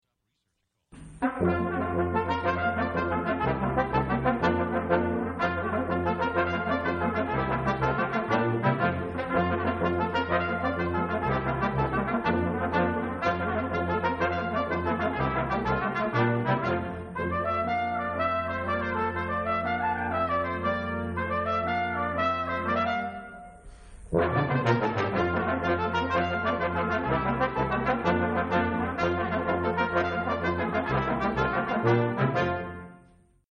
Seton Performance Series - 1/18/2004